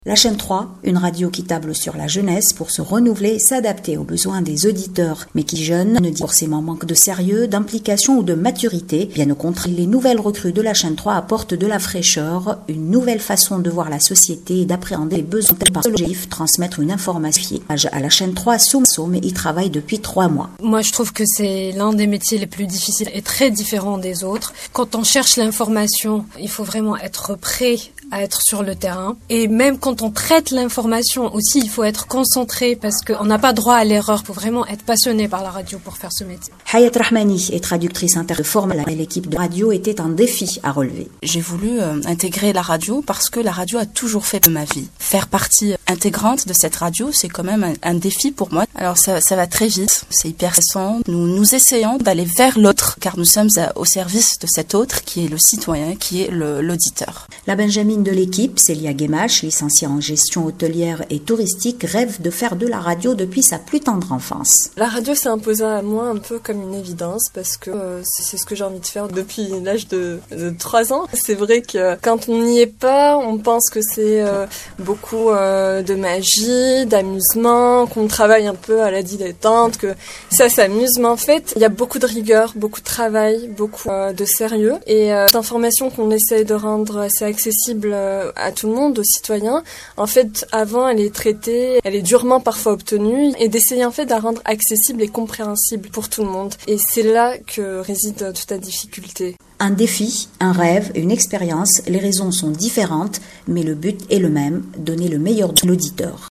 à la radio Chaine 3 Déclarations des nouvelles recrues de la radio